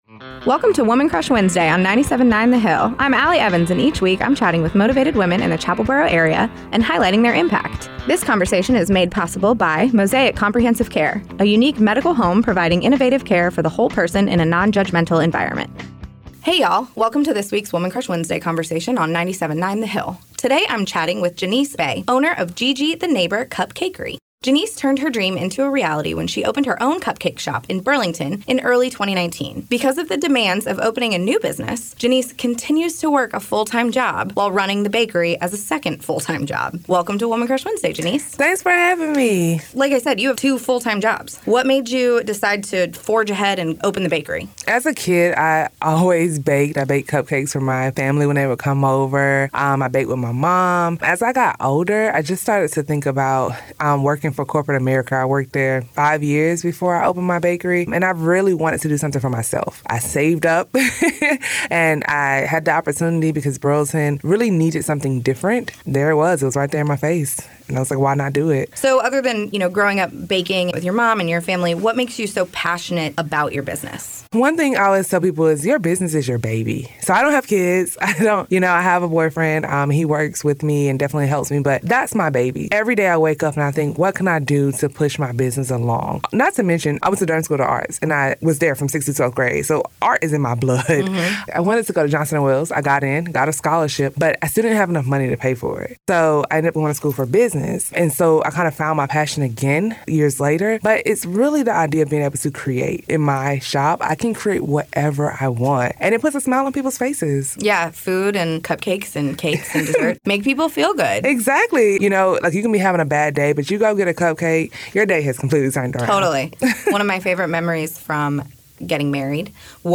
” a three-minute weekly recurring segment made possible by Mosaic Comprehensive Care that highlights motivated women and their impact both in our community and beyond.